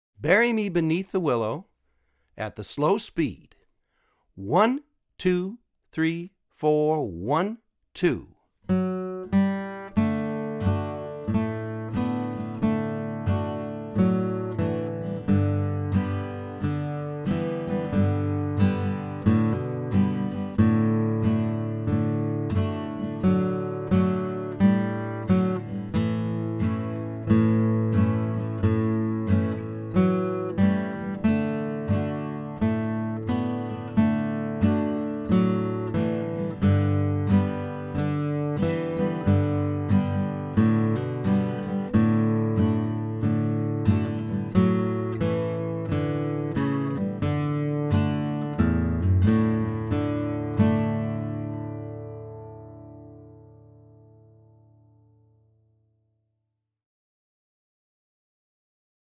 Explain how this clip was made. Online Audio (both slow and regular speed)